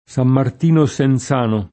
Sam mart&no SenZ#no]), San Martino al Cimino [